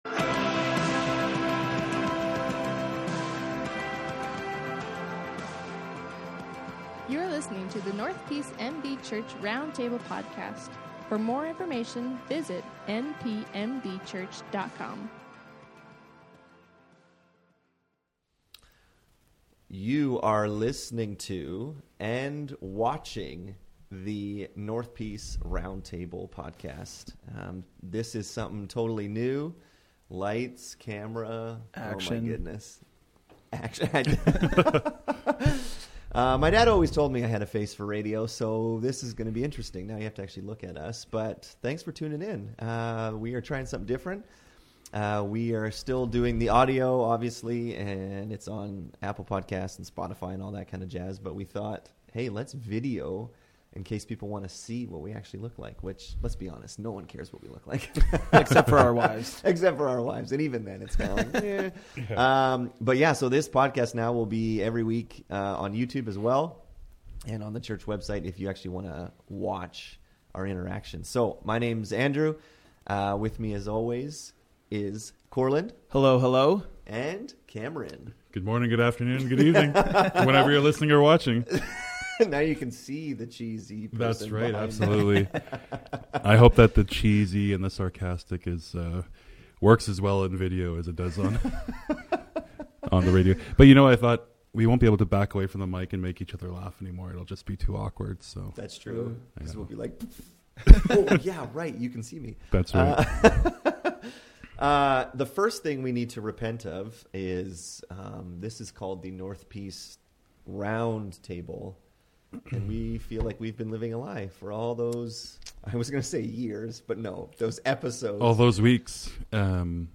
In this episode of the roundtable podcast, the guys discuss creation. How and why did God create the universe?